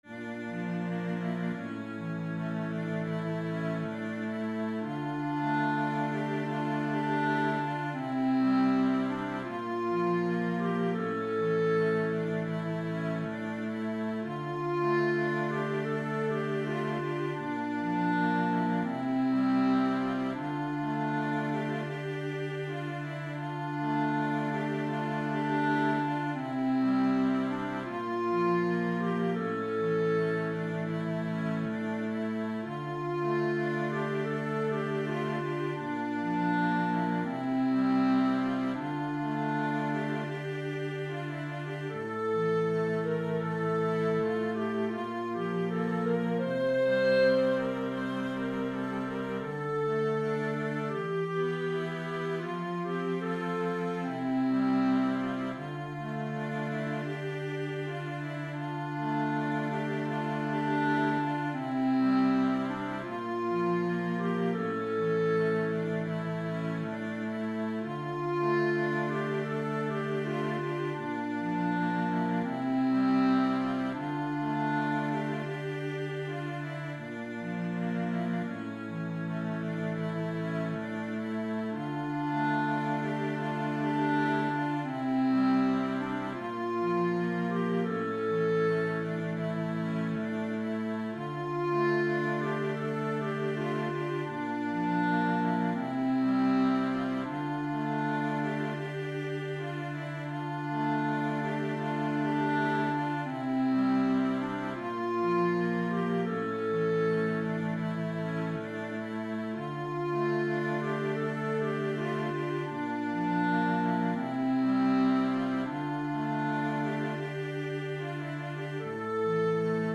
Choir Unison, Organ/Organ Accompaniment
Voicing/Instrumentation: Choir Unison , Organ/Organ Accompaniment